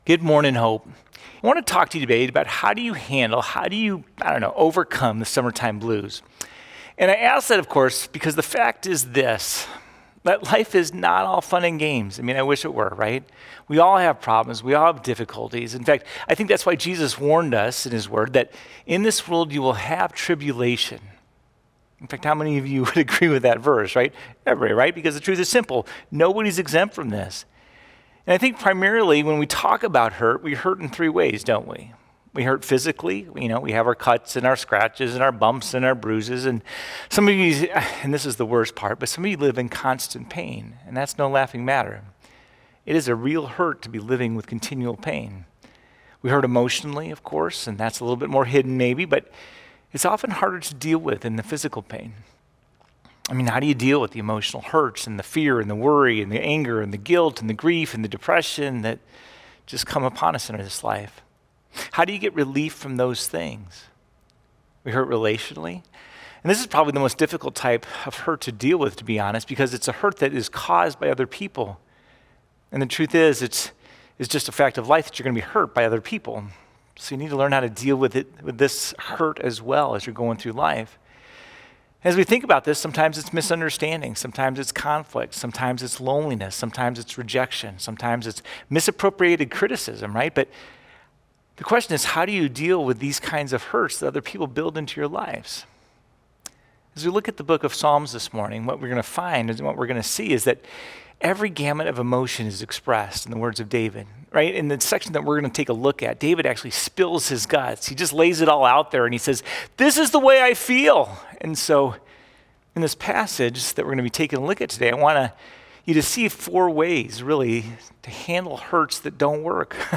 08-31-Sermon.mp3